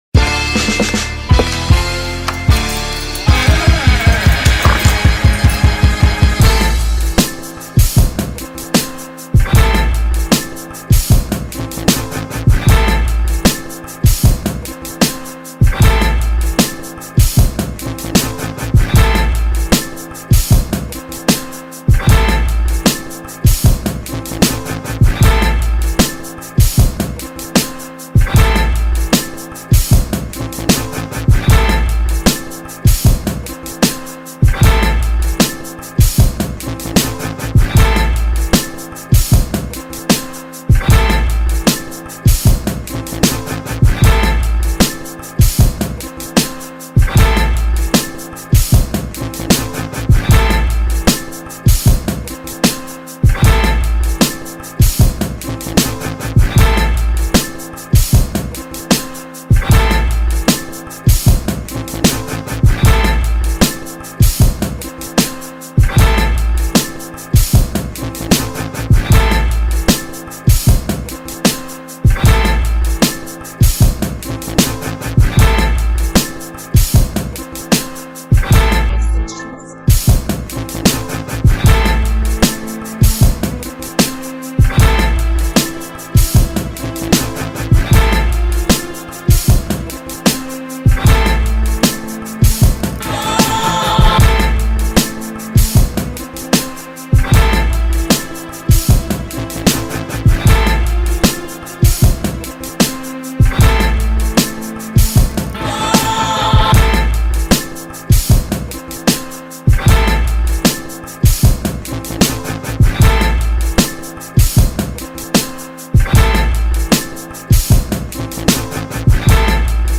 official instrumental
Boom Bap Instrumental